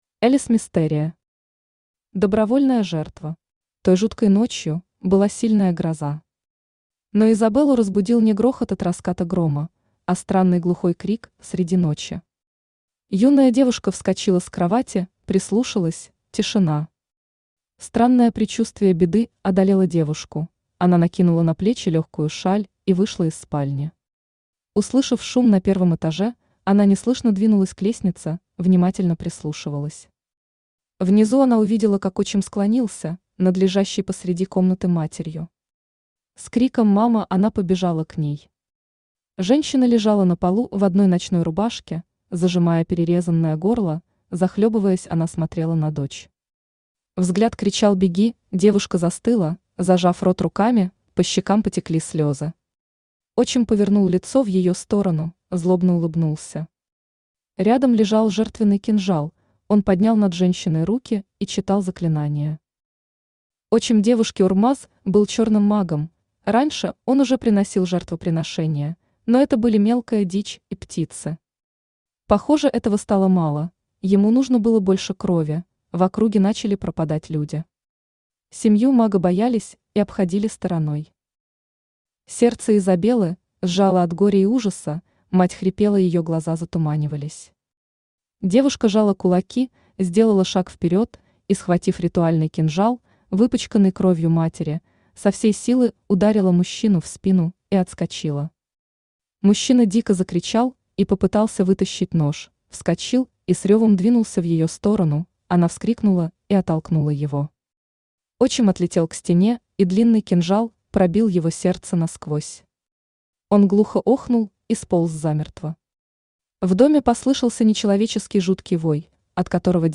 Аудиокнига Добровольная жертва | Библиотека аудиокниг
Aудиокнига Добровольная жертва Автор Элис Мистерия Читает аудиокнигу Авточтец ЛитРес.